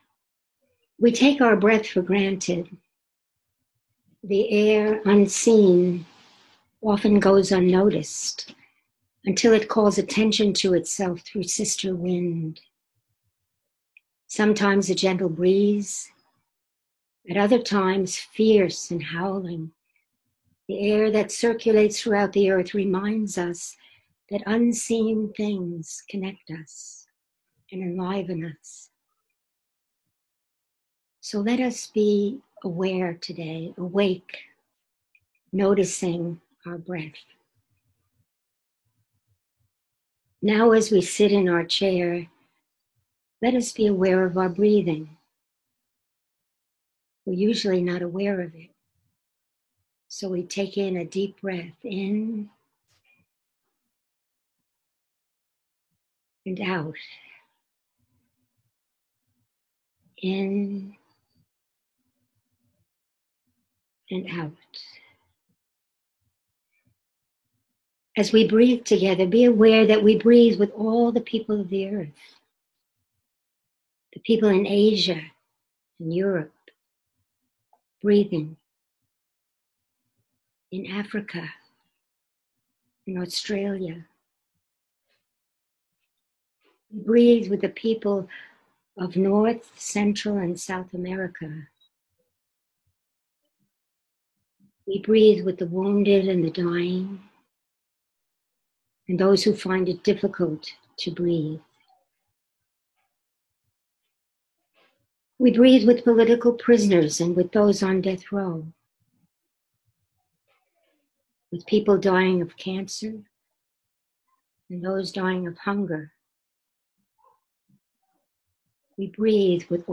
Meditation about Breath